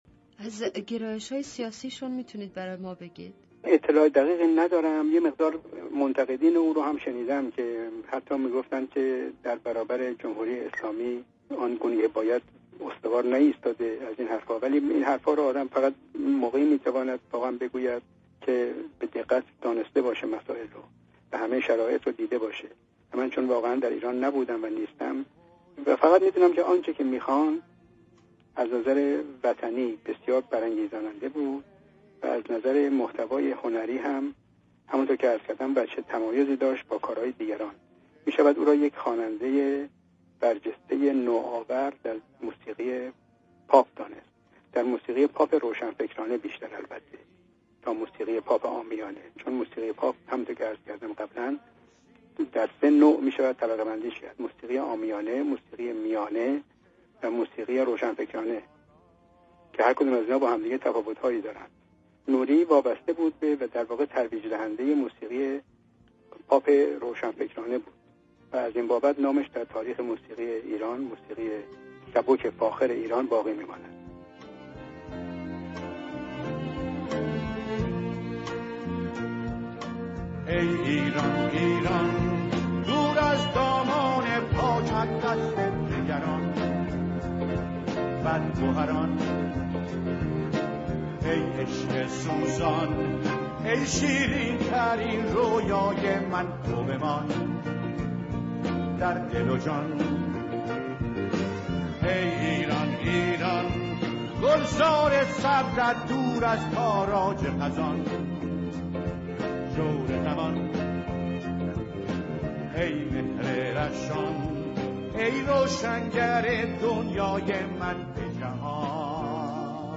Radio Zamaneh Interview